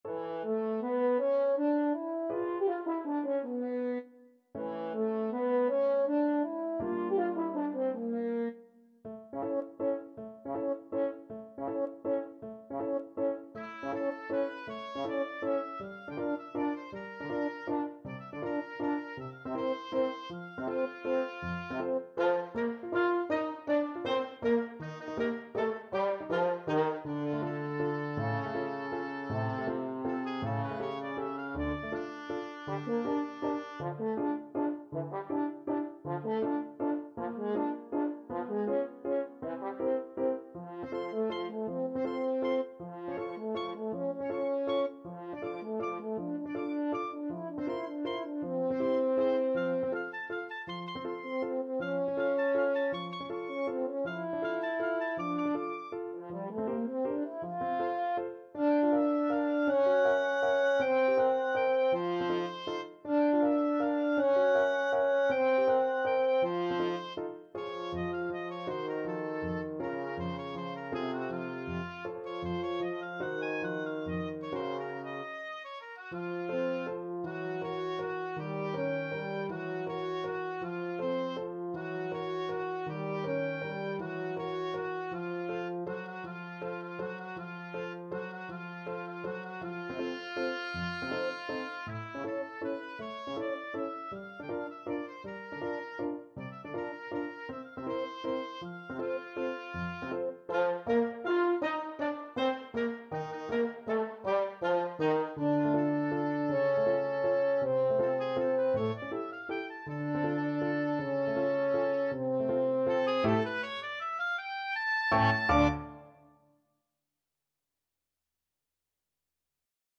Oboe
French Horn
Allegretto = 160
3/4 (View more 3/4 Music)